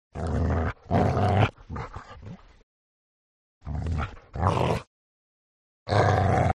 دانلود صدای دندان قرچه کردن و تهدید کردن سگ از ساعد نیوز با لینک مستقیم و کیفیت بالا
جلوه های صوتی